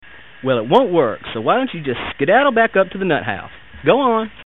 Tags: Radio The Curse Of Dracula Play Horror Bram Stoker